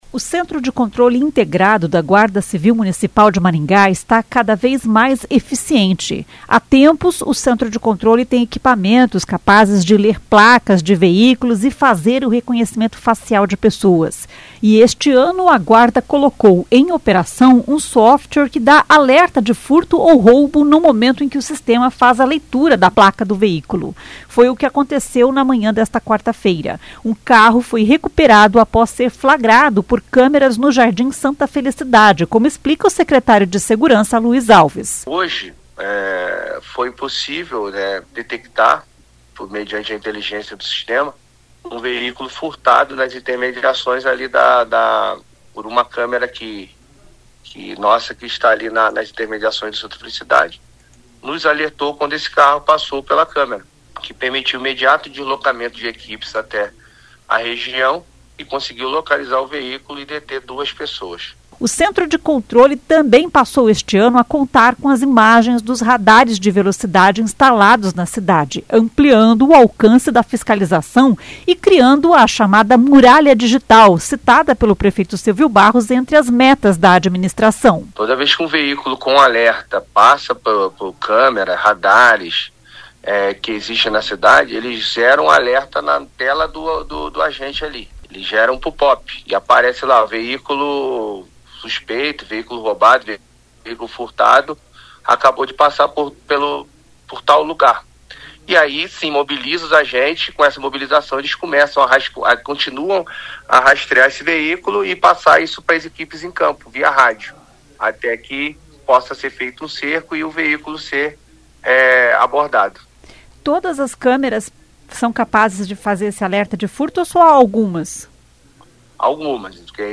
Um carro foi recuperado após ser flagrado por câmeras no Jardim Santa Felicidade, como explica o secretário de Segurança Luiz Alves.